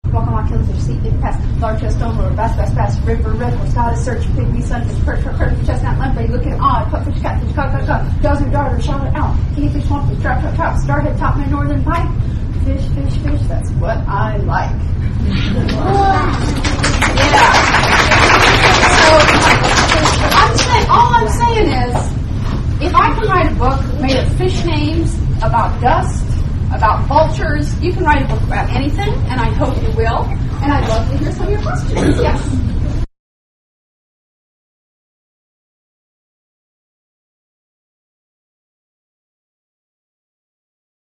Here I perform a high speed version of the
Fish-Chant-End-Fast.mp3